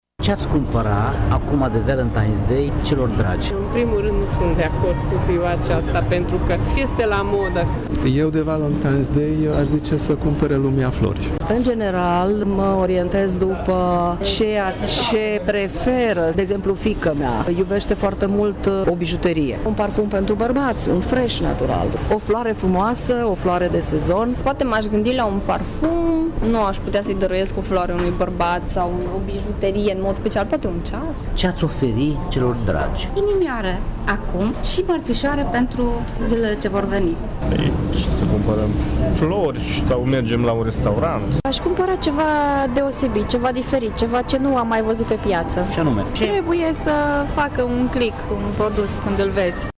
Nu toți cumpărătorii sunt de acord cu această zi, pe care o consideră o modă importată. Alții, dacă se decid, fac cumpărături specifice de primăvară, și sunt deja cu gândul la mărțișoare.